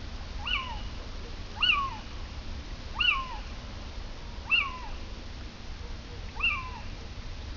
ATHENE NOCTUA - LITTLE OWL - CIVETTA
DATE/TIME: 01/february/2004 (6 p.m.) - IDENTIFICATION AND BEHAVIOUR: one female (not seen) is perched somewhere in a cultivated field with scattered olive trees. It seems to answer to the male hoots (see Recording 1) - POSITION: Poderone near Magliano in Toscana, LAT.N 42°36'/LONG.E 11°17' - ALTITUDE: +130 m. - VOCALIZATION TYPE: a series of loud "kew" calls. This is the commonest call. - SEX/AGE: adult female - COMMENT: probably it is a female answering to her mate. Average time interval between calls: 1,17 s. (S.D. = 0,49; Range = 0,7/2,5; n = 13). - MIC: (U)